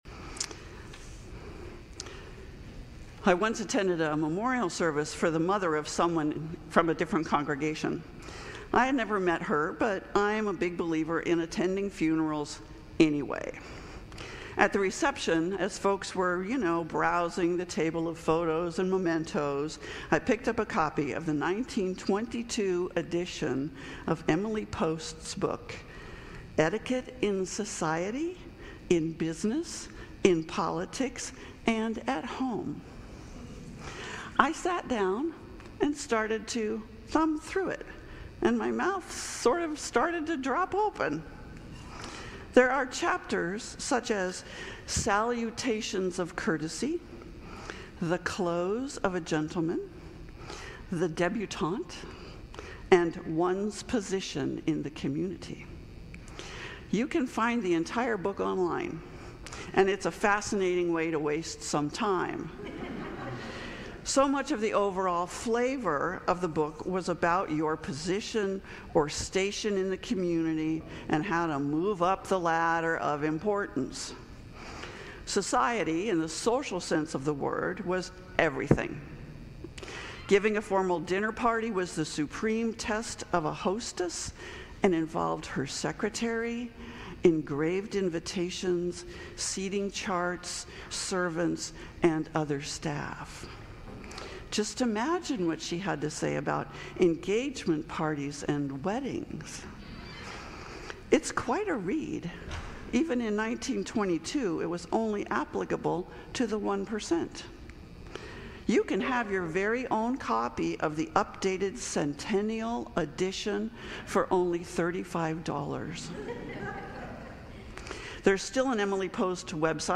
Sermons | Grace Episcopal Church